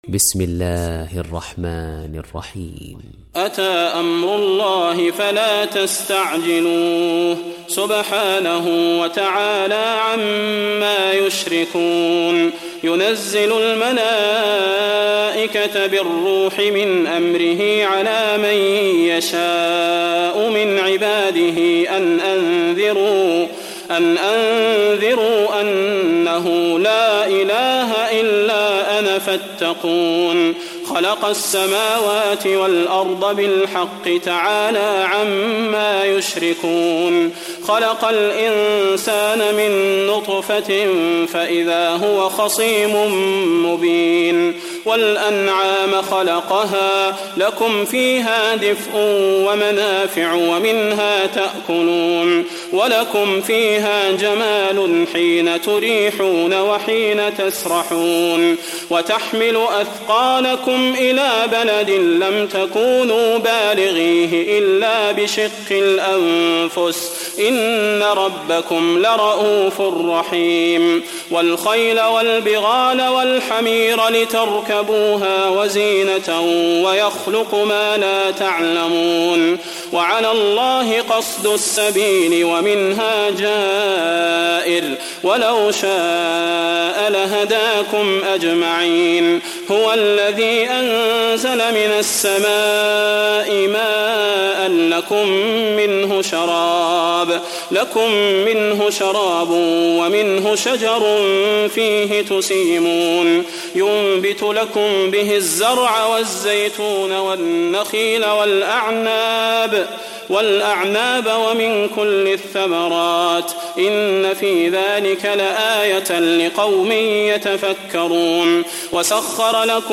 Nahl Suresi İndir mp3 Salah Al Budair Riwayat Hafs an Asim, Kurani indirin ve mp3 tam doğrudan bağlantılar dinle